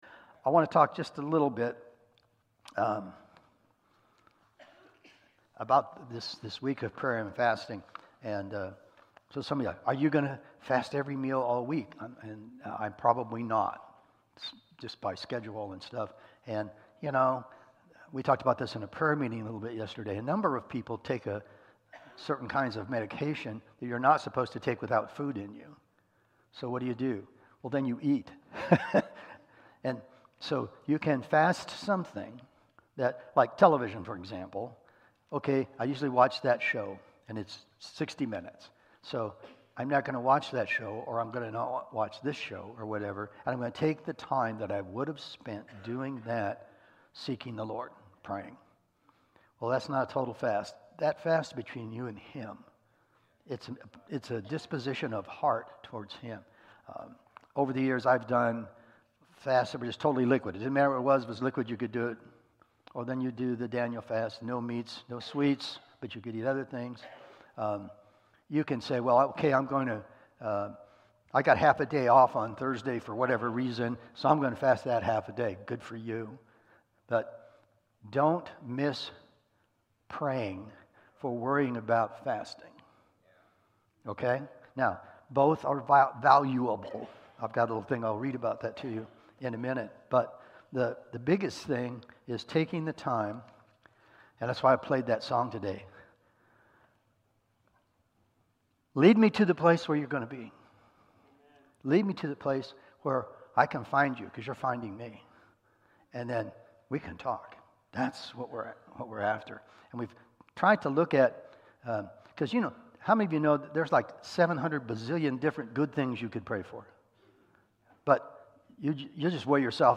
James 5:17-18 Service Type: Sunday Morning Sermon Download Files Notes Topics: Prayer and Fasting